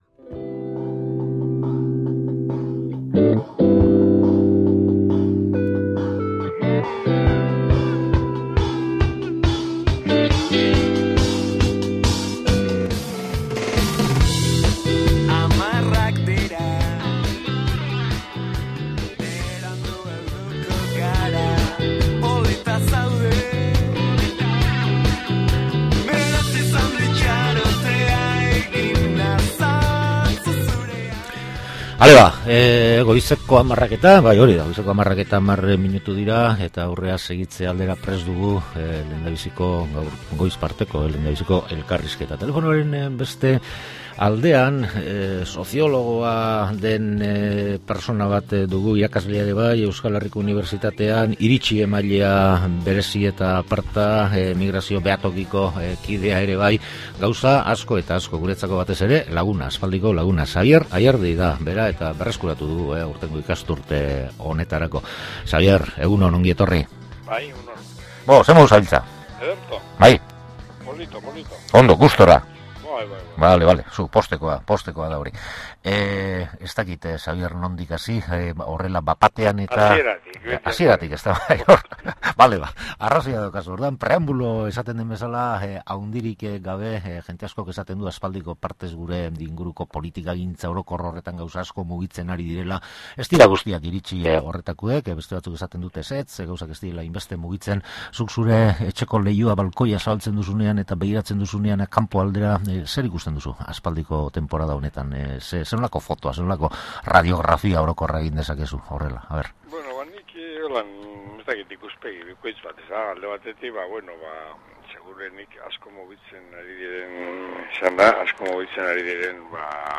Eskertuko duzun elkarrizketa, entzule.